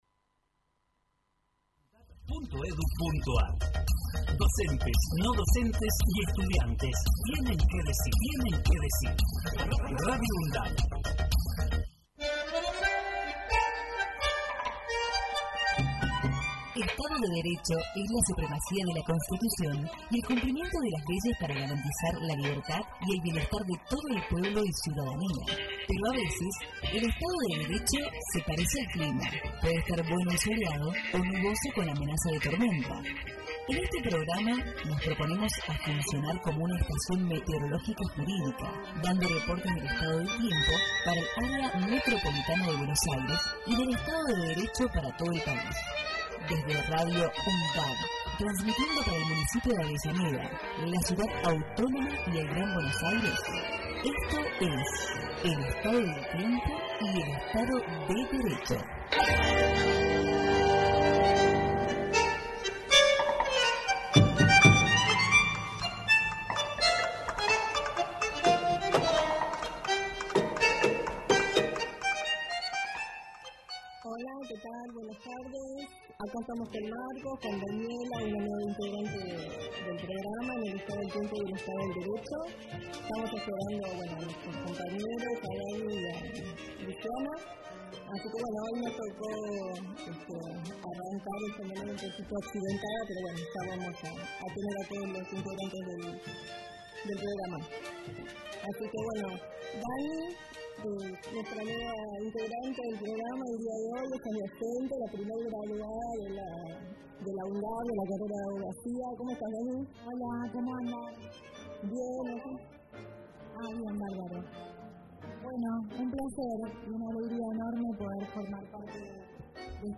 es un programa realizado por estudiantes y docentes de la carrera de Abogacía